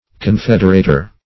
Confederator \Con*fed"er*a`tor\, n.